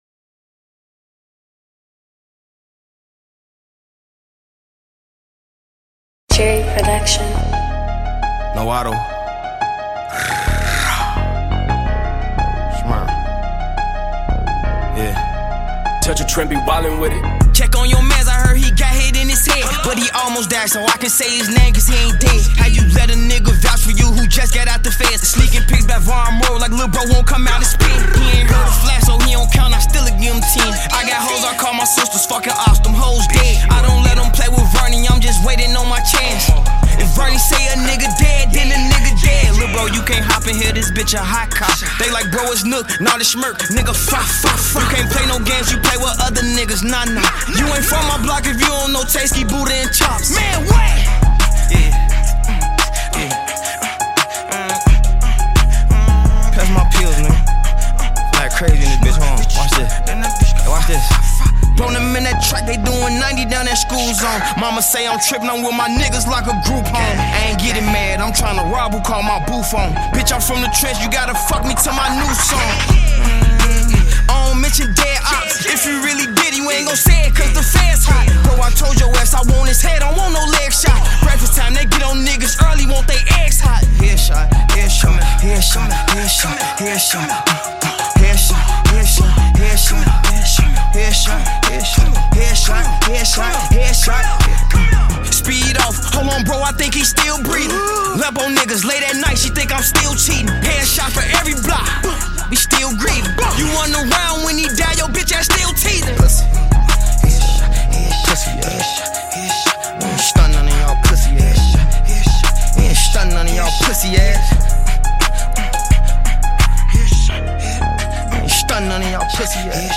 Popular American rapper